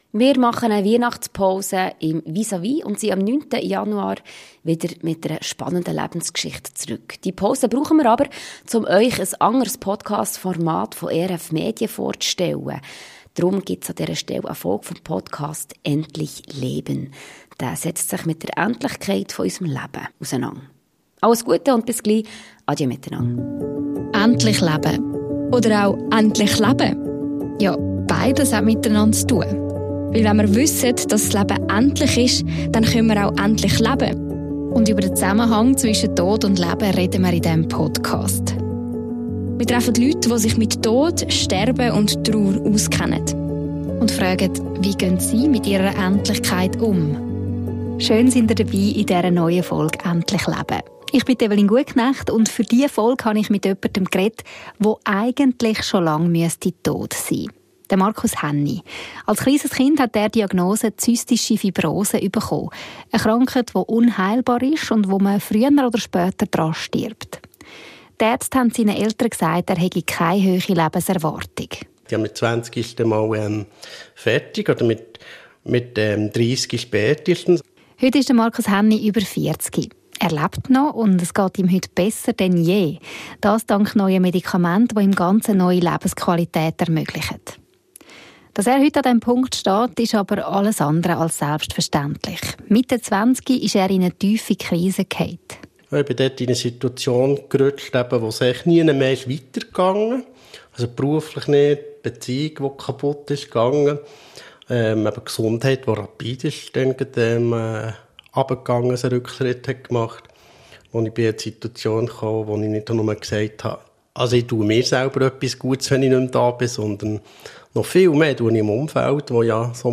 Ein Gespräch über Spitalaufenthalte, Träume, Rückschläge, Glaube und Hoffnung.